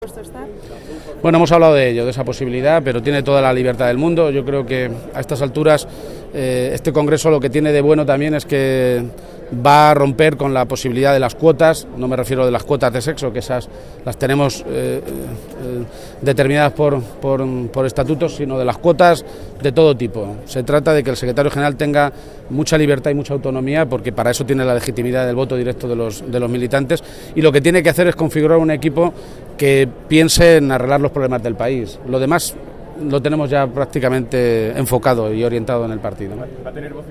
García-Page se pronunciaba de esta manera esta mañana, en Madrid, a su llegada al Congreso Extraordinario en el que el PSOE de toda España ratificará a Pedro Sánchez como nuevo secretario general y elegirá a su nueva dirección.